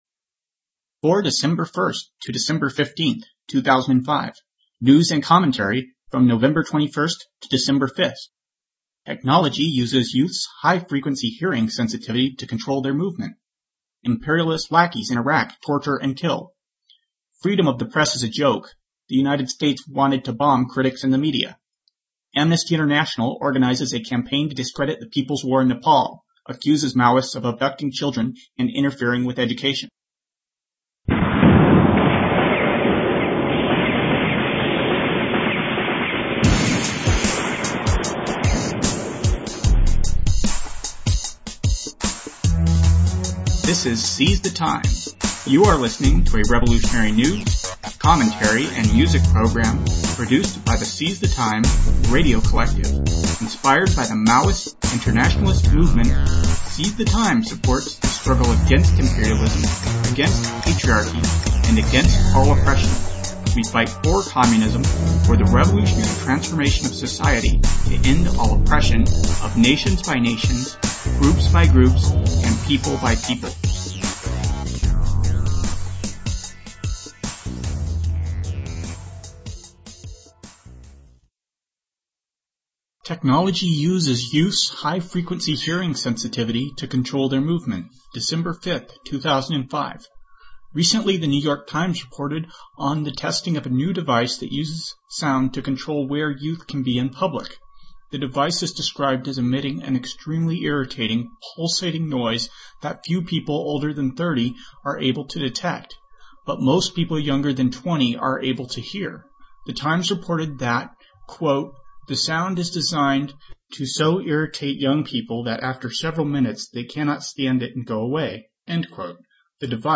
Revolutionary news, commentary and music program produced by the Seize the Time Radio Collective.